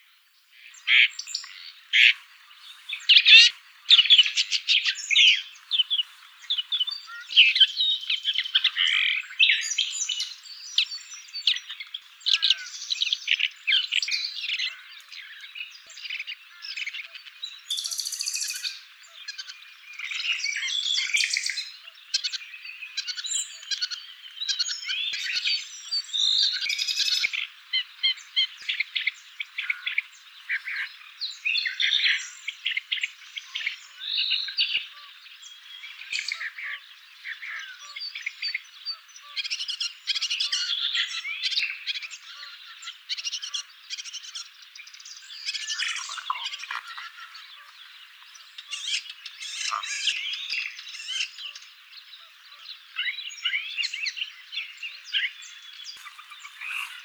Lanius senator - Woodchat shrike - Averla capirossa
DATE/TIME: 26/april/2020 (6 a.m.) - IDENTIFICATION AND BEHAVIOUR: Surely two (but probably three) birds are perched in sight on a power line. They leave the wire and dive from time to time in a thick oak copse, from where they come back to the power line. Zone with hedgerows, oaks and vineyards. - POSITION: Poderone near Magliano in Toscana, LAT.N 42 36'/LONG.E 11 17'- ALTITUDE: +130 m. - VOCALIZATION TYPE: Song. - SEX/AGE: adult birds, male (and female?) - COMMENT: The audio sample is part of a longer recording, it is a low quality sample, because the Woodchat shrikes are relatively far and their soft song is often covered or blurred by Nightingales, Cuckoos and other passerine birds. The intrusive syllables were erased from the spectrograms, but they sometimes can still be heard in the audio sample. There is some mimicry (see spectrograms). Background: bird dawn chorus and human voice. - MIC: (P)